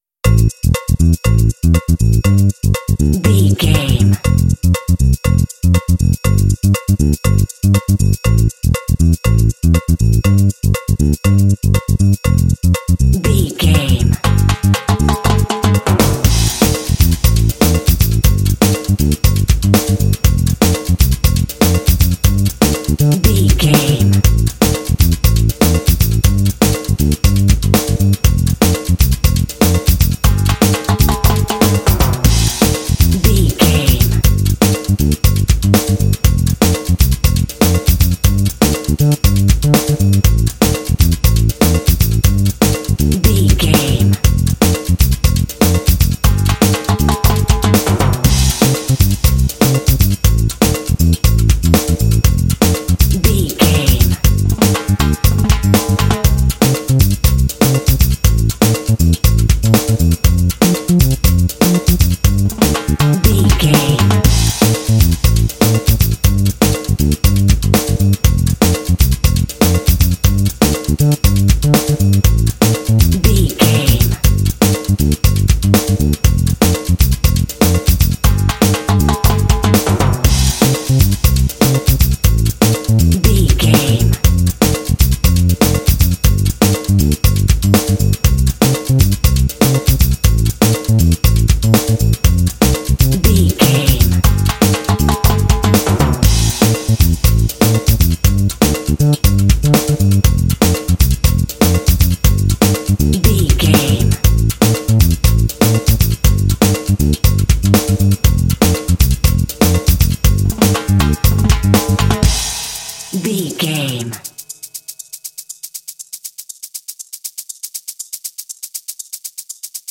Uplifting
Aeolian/Minor
smooth
lively
driving
percussion
drums
bass guitar
latin